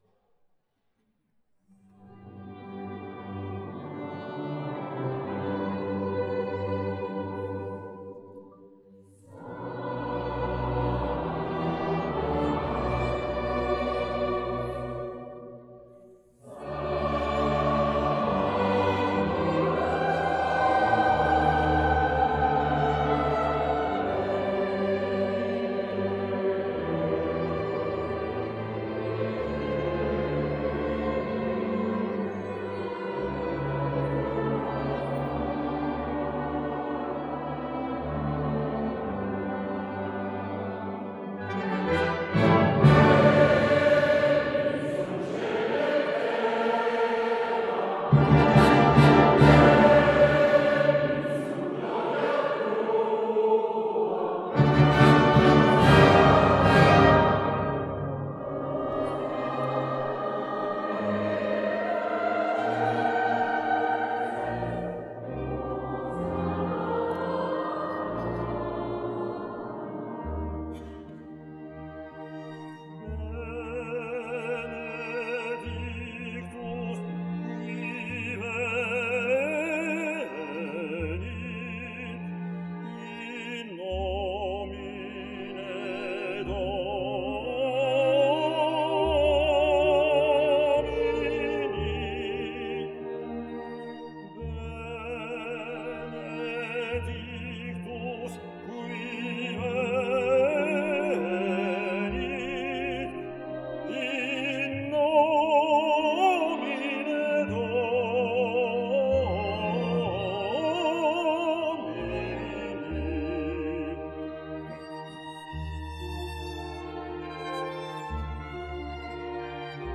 D’autres vidéos vont suivre dans les prochains. jours, mais nous vous proposons ci-dessous l’intégralité du concert en version audio pour vous permettre de vivre ou revivre ce sublime moment!
Les jeunes musiciens ont apporté  fraîcheur et énergie nouvelle à l’interprétation.
Les applaudissements ont fusé dans l’église, remerciant chaleureusement tous les artistes pour ce magnifique moment de musique.